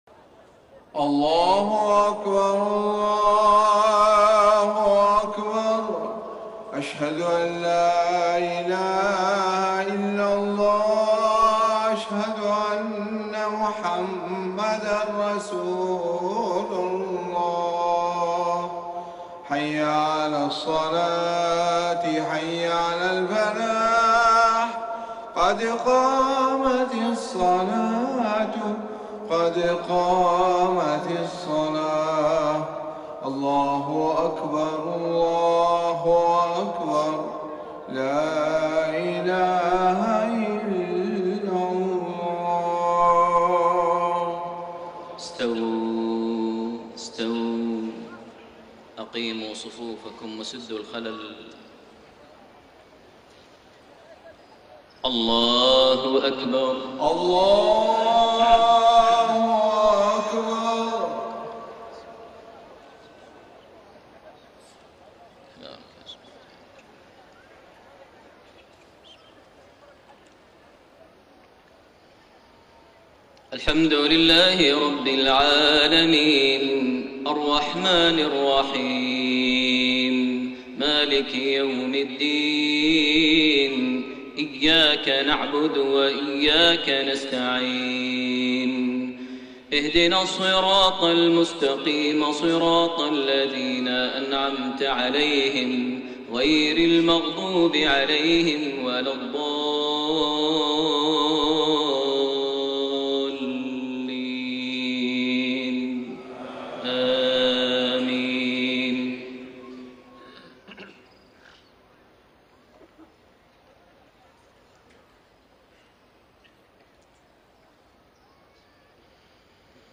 صلاة المغرب 19 جمادى الآخرة 1433هـ خواتيم سورة الفتح 27-29 > 1433 هـ > الفروض - تلاوات ماهر المعيقلي